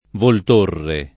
[ volt 1 rre ]